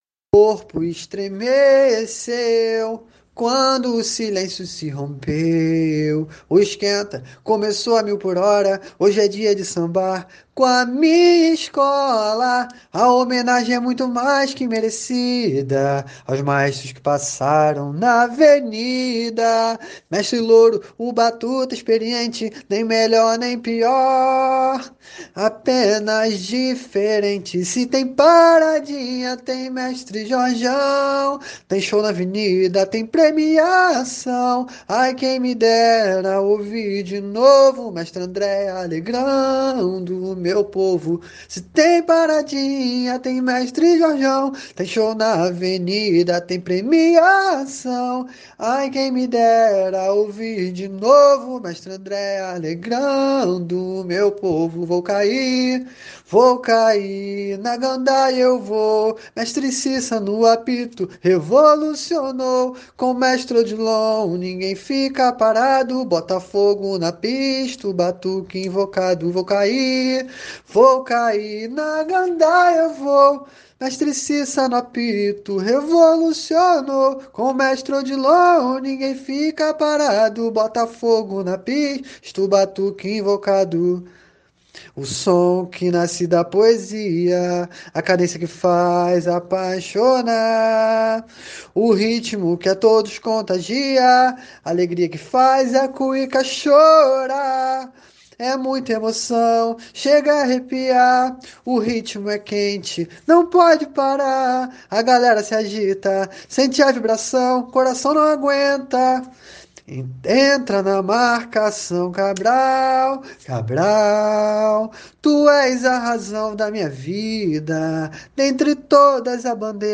Samba  02